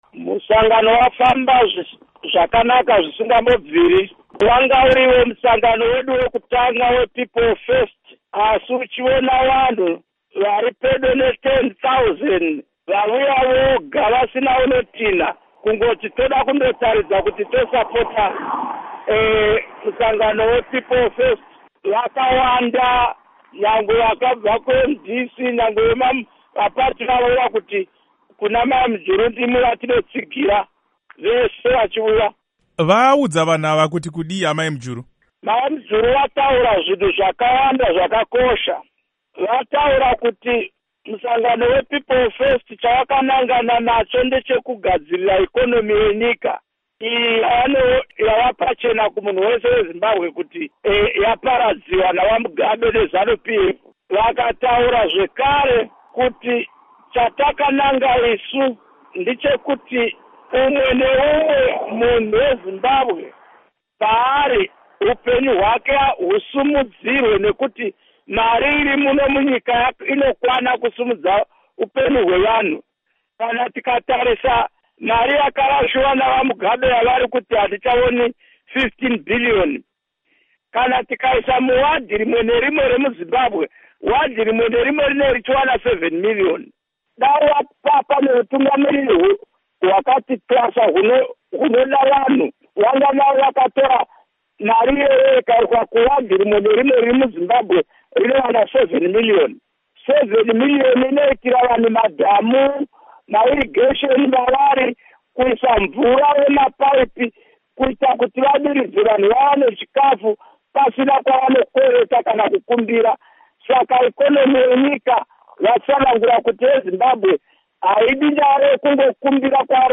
Hurukuro naVaKudakwashe Bhasikiti